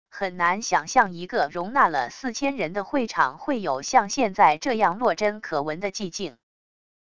很难想象一个容纳了4000人的会场会有像现在这样落针可闻的寂静wav音频生成系统WAV Audio Player